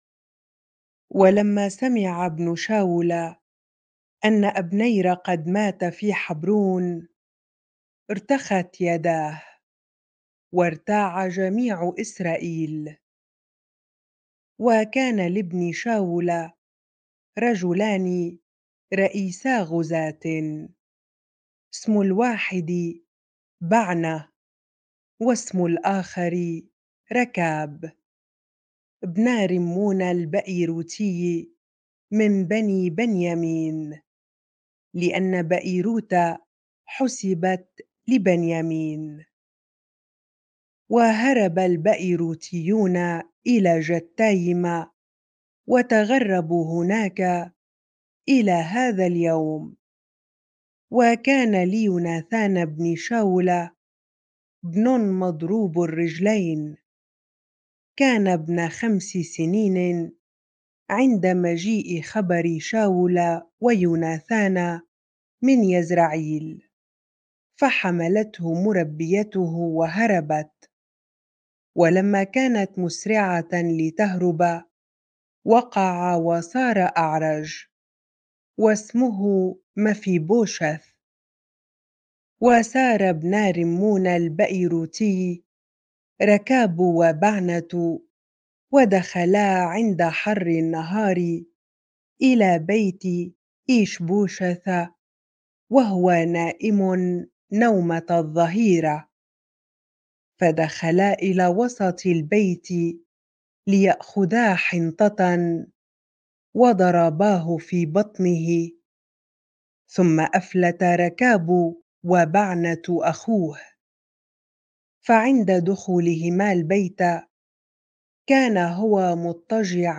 bible-reading-2Samuel 4 ar